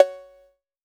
Tr8 Cowbell.wav